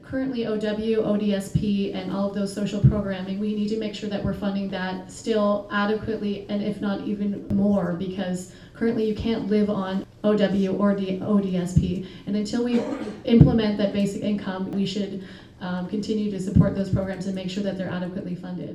A large group of citizens at St. Joseph’s Parish was engaged in the discussion for close to two hours.